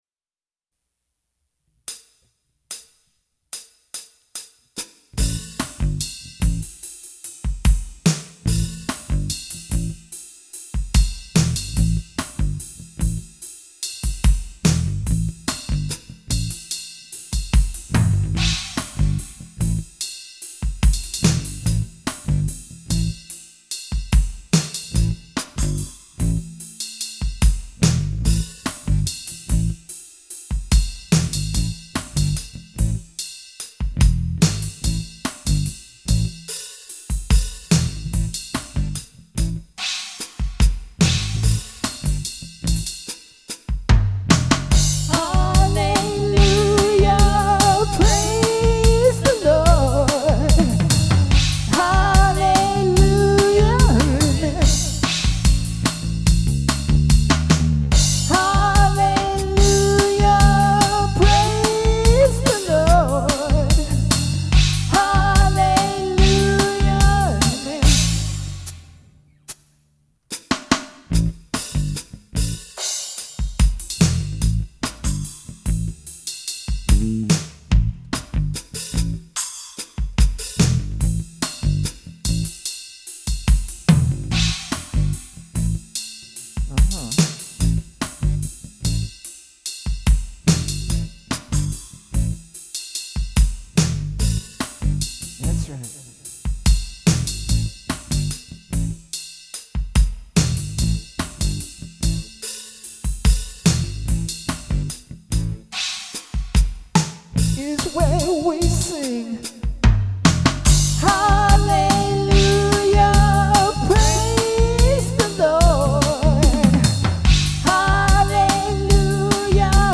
Performance Tracks